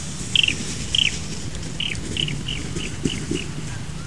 The main aggressive "call" of water deer appears to be a distinctive, high-frequency, click-like sound with an intense, almost metallic quality.
Acoustically, the trilling call has a sharp character not entirely dissimilar to clave percussion.
A typical example of a water deer buck whickering (also referred to as chirruping, chittering, or sometimes clicking) while chasing away a rival during the rut.